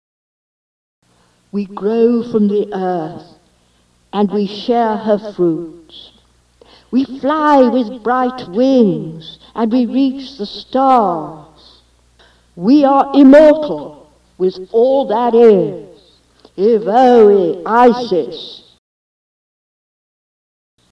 Credo: